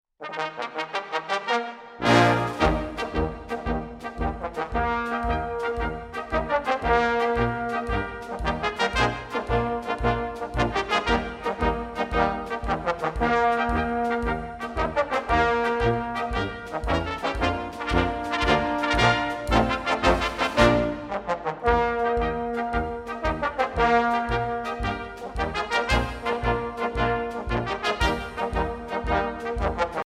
Tentet - Giant Brass
March